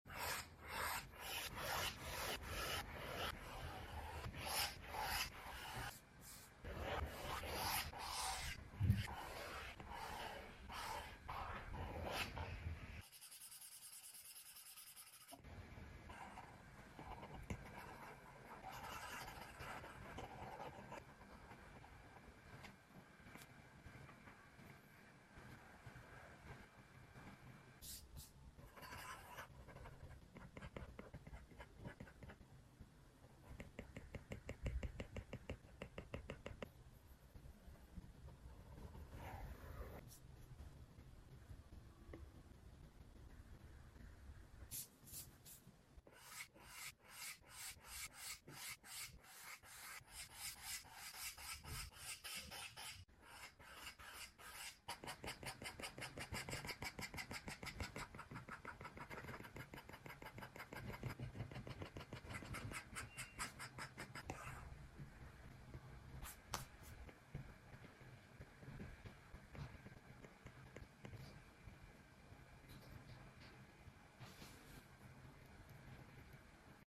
Initially, I started making the video just for ASMR and to document the creation process of each of my drawings.